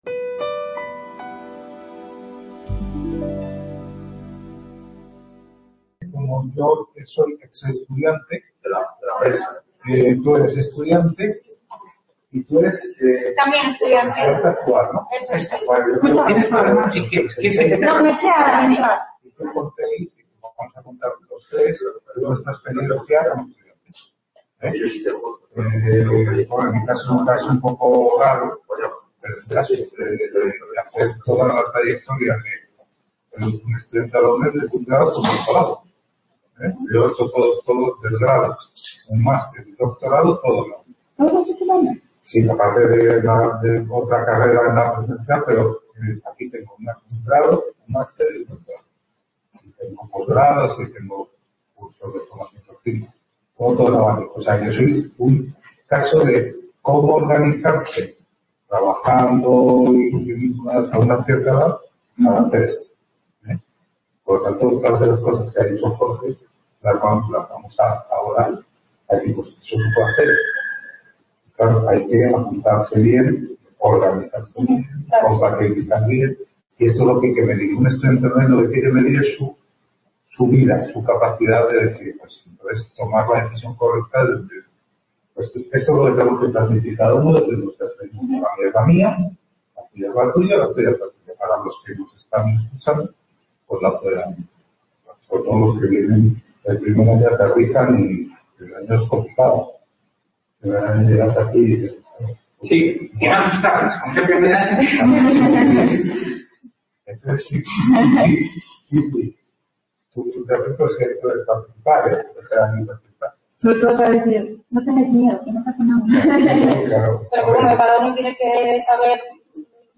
Mesa Redonda Estudiantes veteranos/as y Alumni UNED…
Experiencias contadas por estudiantes de la UNED
CA Ponferrada - Jornadas de Acogida para Estudiantes y Tutores/as curso 20/21